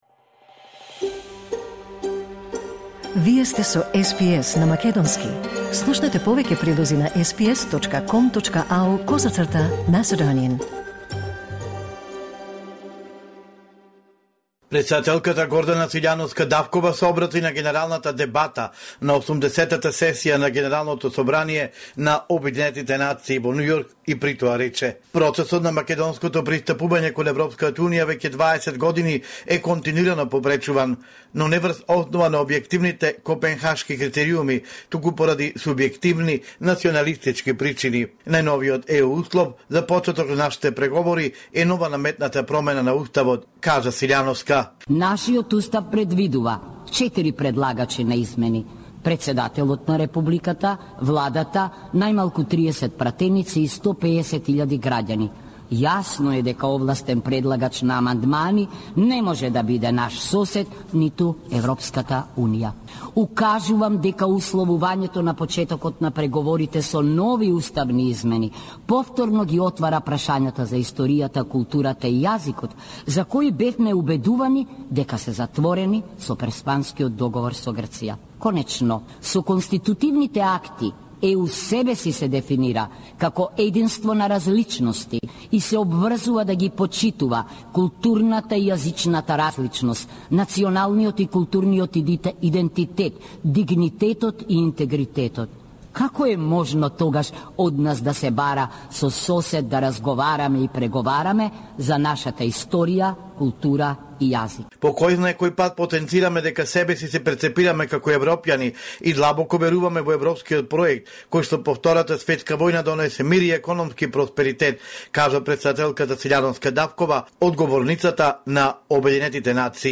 Извештај од Македонија 26 септември 2025